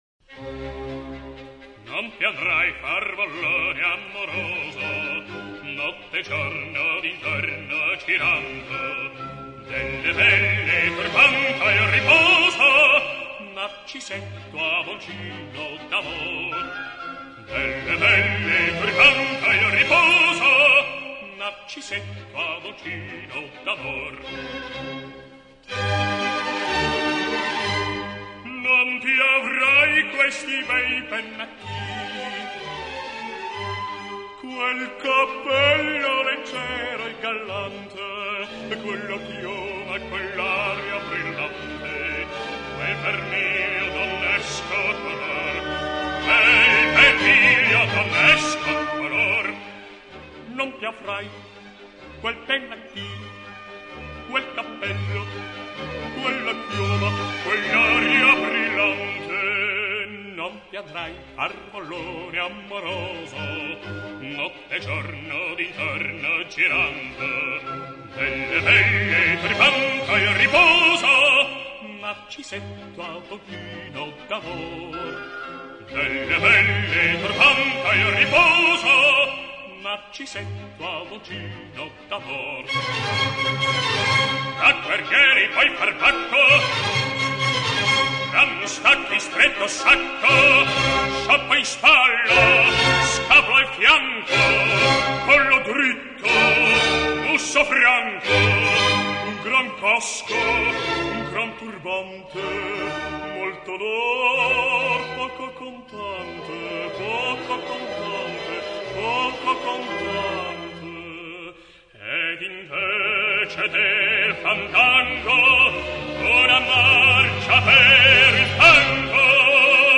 Классическая вокальная музыка.
Арии из Опер.